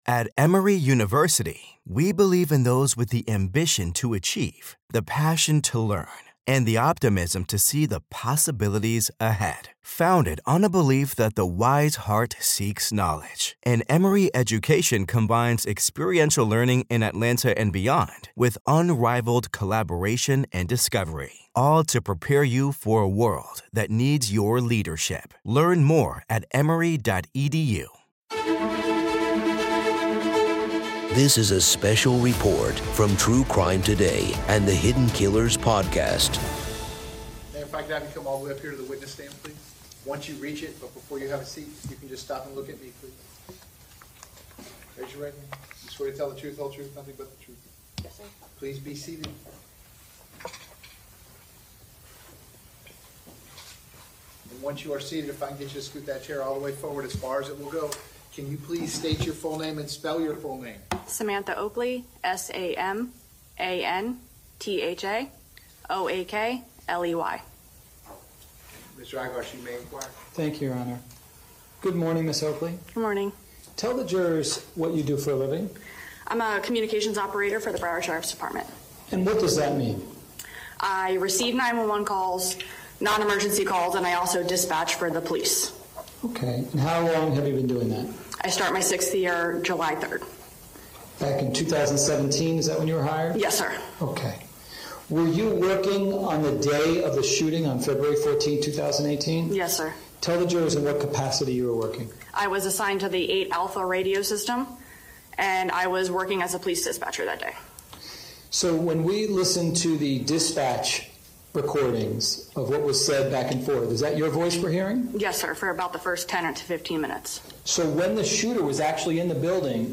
Listen as the prosecution and defense present their cases, with firsthand testimonies and critical evidence painting a gripping narrative of the tragic day. Discover the trial’s key moments and turning points and gain in-depth insights into the legal strategies and consequences at play.